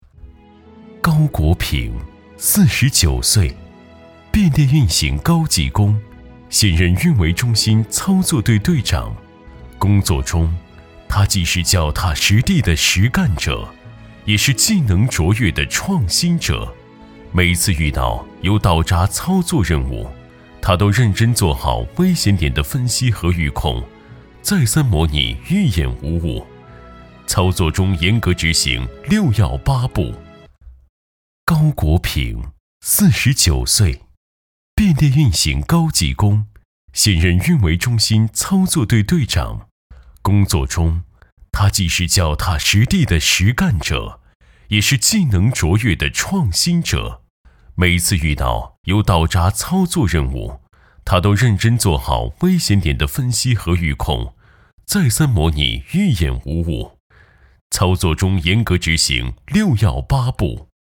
职业配音员，拥有国家普通话测试一级甲等证书，声音大气浑厚，擅长专题类，宣传类稿件。